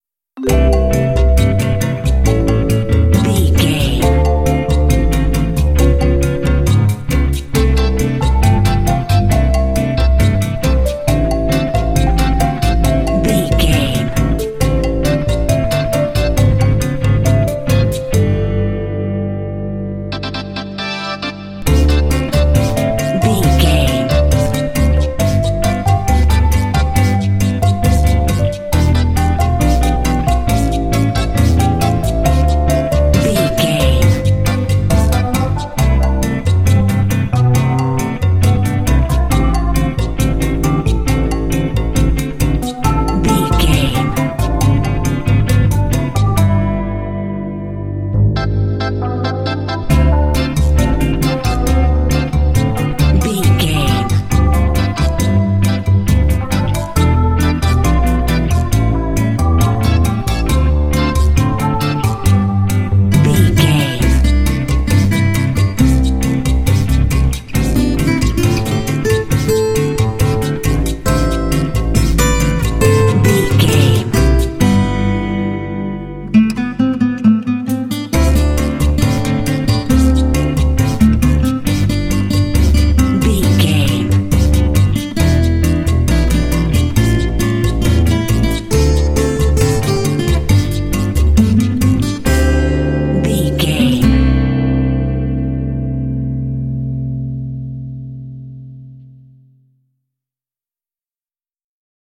Ionian/Major
light
playful
uplifting
calm
cheerful/happy
electric guitar
bass guitar
percussion
electric organ
electric piano
underscore